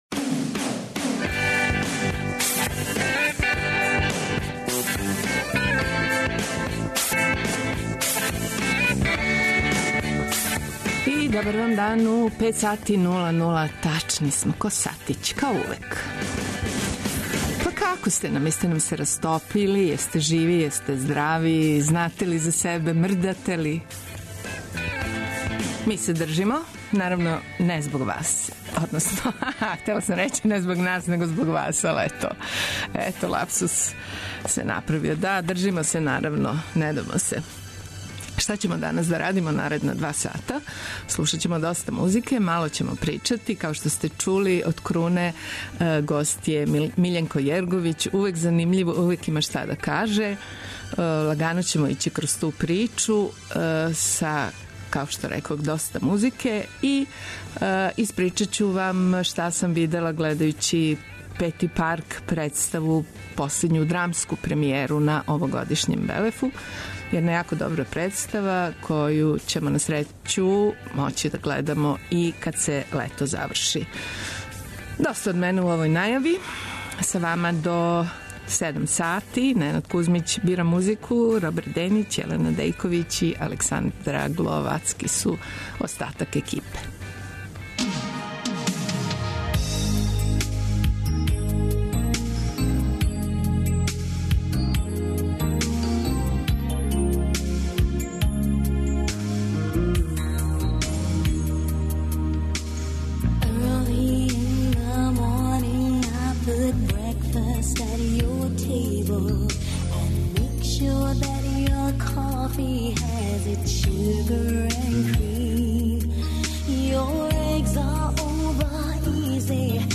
Гост емисије је Миљенко Јерговић, један од водећих писаца ex-yu простора, рођен 1966. у Сарајеву, где је дипломирао филозофију и социологију на Филозофском факултету.